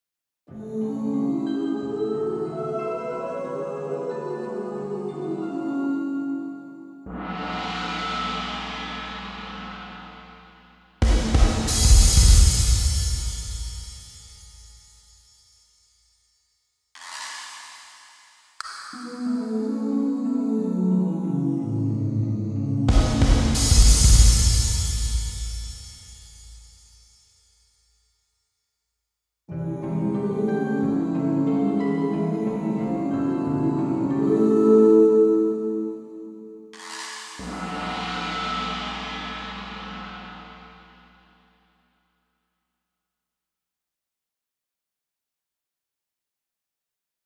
where for example these are the ranges of the NOTION 3 Harp
which are heard in the fabulous Avant Garde genre song "Thundering Silence"
[NOTE: This is done with music notation in NOTION 3 using a combination of virtual instruments from Addictive Drums (XLN Audio), NOTION 3, and SampleTank (IK Multimedia). Some of the instruments are enhanced with effects processors from BBE and T-RackS (IK Multimedia), and it is mastered with T-RackS using headphones, mostly because I am too lazy to switch to loudspeaker mixing at the moment . . .